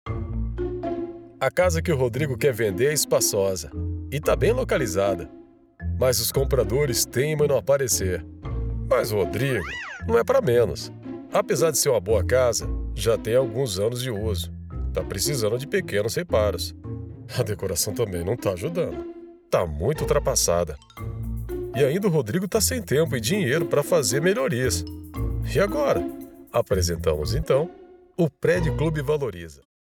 Brazilian voiceover, portuguese voiceover, brazilian voice actor, brazilian voice talent, brazilian portuguese voiceover, brazilian portuguese voice talent, brazilian portuguese voice actor, brazilian male voiceover, portuguese male voiceover, brazilian elearning voice, brazilian voice artist, brazilian portuguese
Sprechprobe: Industrie (Muttersprache):